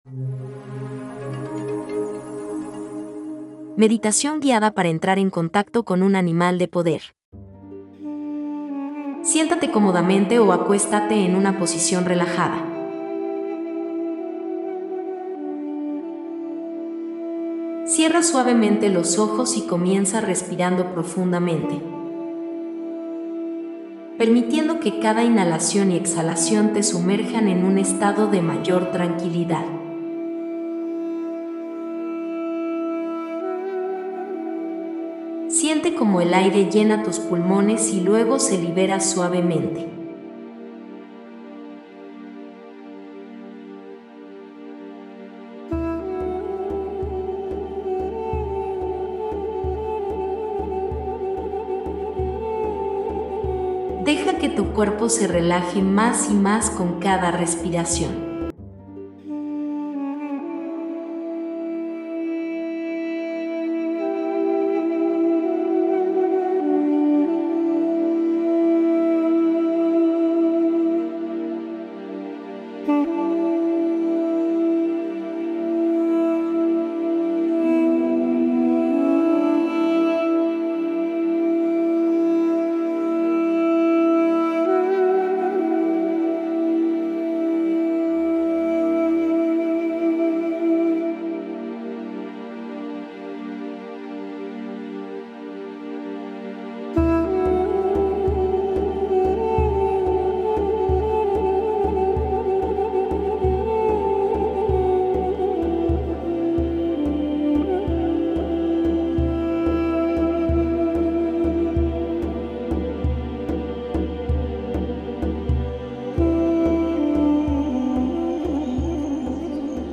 MEDITACIÓN GUIADA